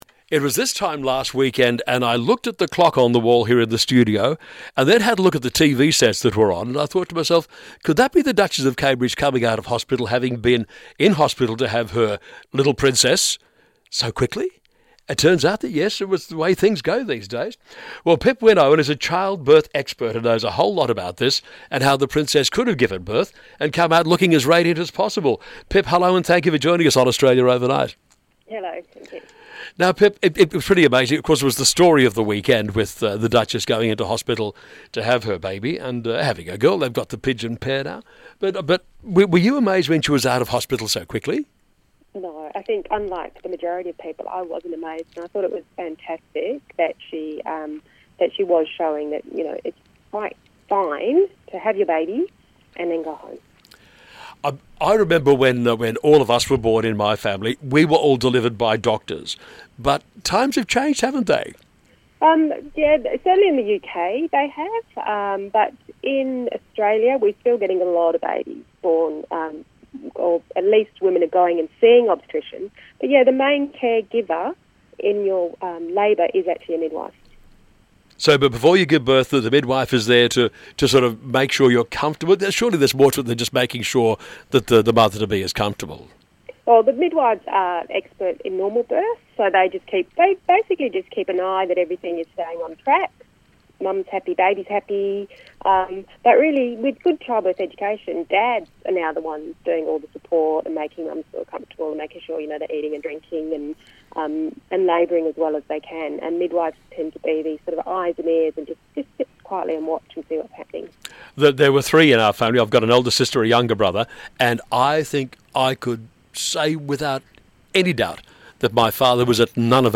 And I even managed  to do two radio interviews talking about Kate’s natural birth and the fact she left hospital so soon.